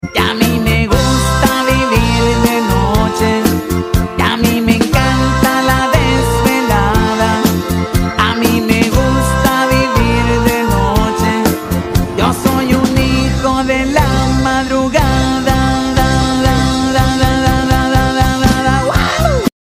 El sonido dentro del casco sound effects free download